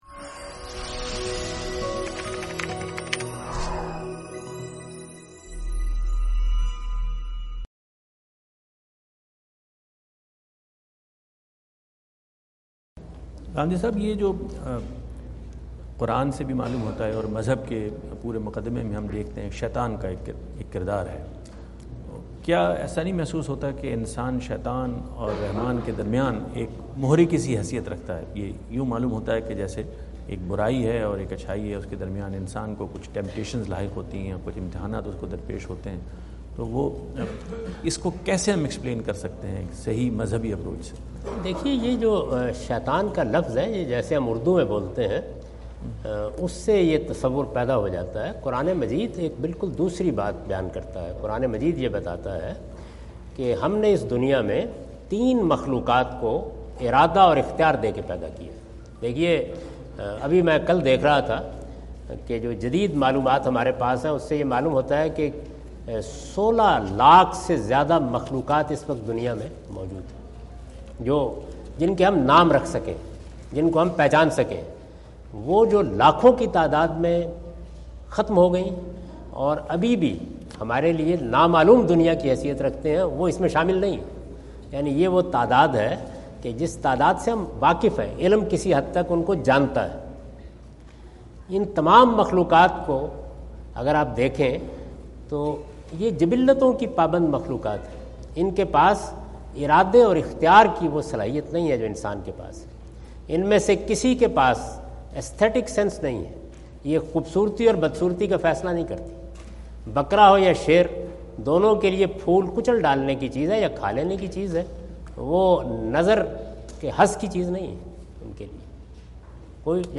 Category: English Subtitled / Questions_Answers /
Javed Ahmad Ghamidi answer the question about "Role of Satan in Religion" During his US visit in Dallas on September 17, 2017.